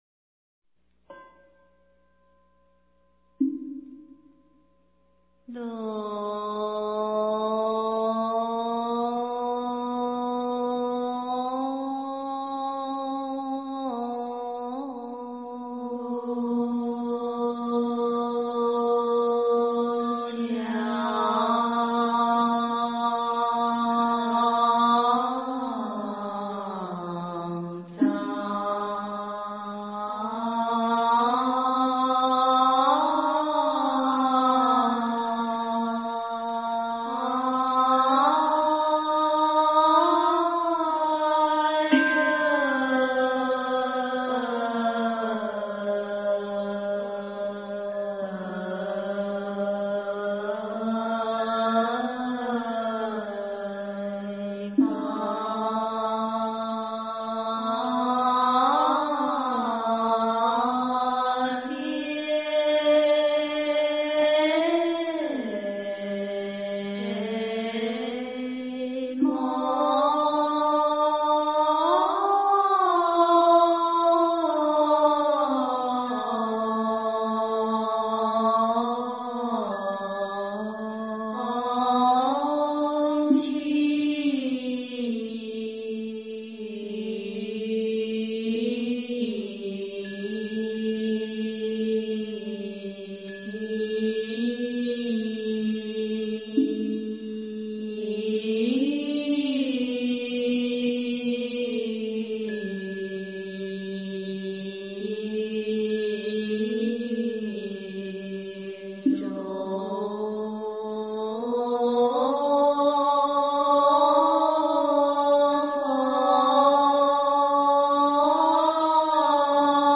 普门品--灵岩山寺 经忏 普门品--灵岩山寺 点我： 标签: 佛音 经忏 佛教音乐 返回列表 上一篇： 妙法莲华经观世音菩萨普门品--佛光山梵呗团 下一篇： 摩诃般若菠萝蜜--佚名 相关文章 佛宝赞--圆光佛学院众法师 佛宝赞--圆光佛学院众法师...